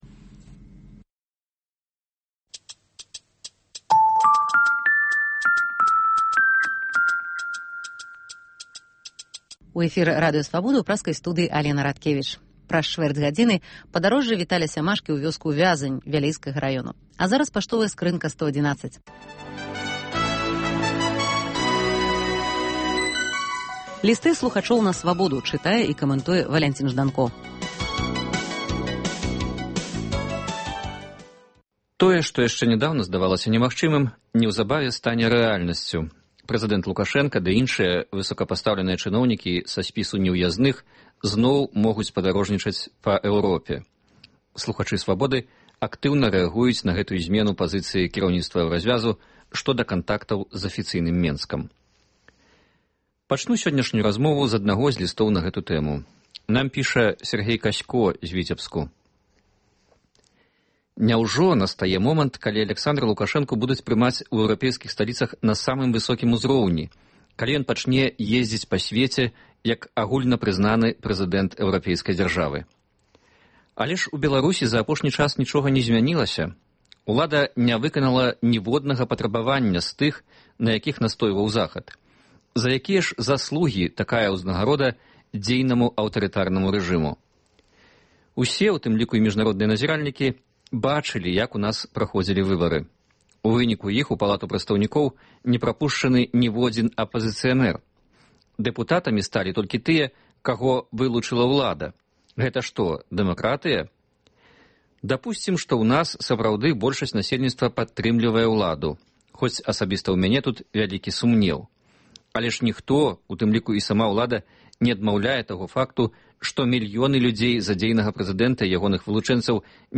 Абмеркаваньне актуальных тэмаў за круглым сталоў